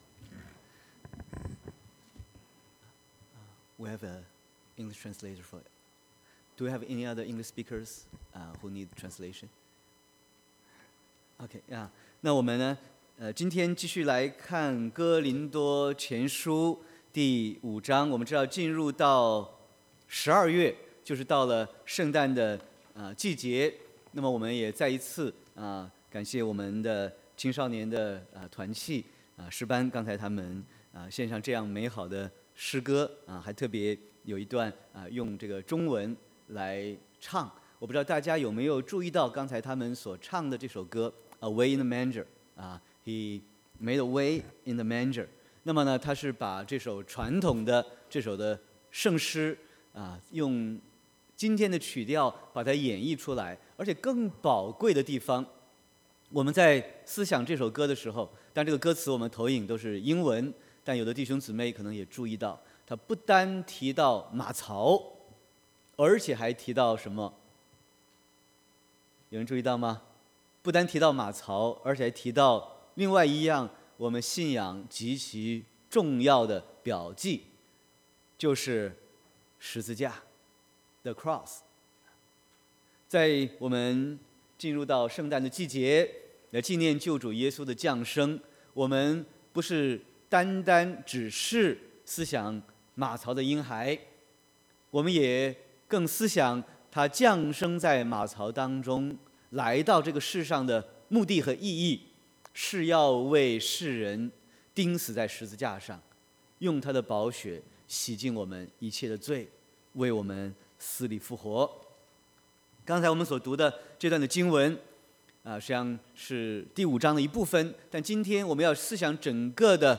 Sermon 12/2/2018